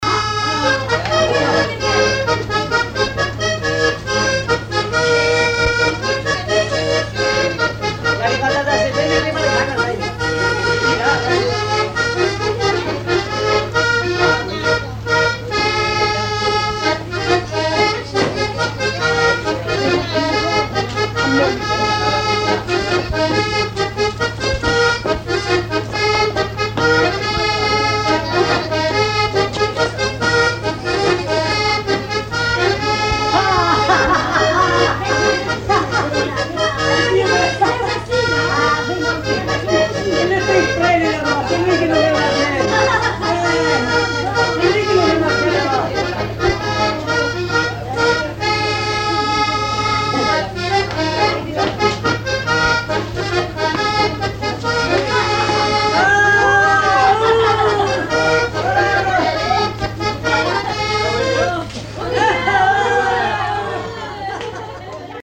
danse : ronde : grand'danse
danses à l'accordéon diatonique et chansons, veillée à l'Abbaye d'Orouet
Pièce musicale inédite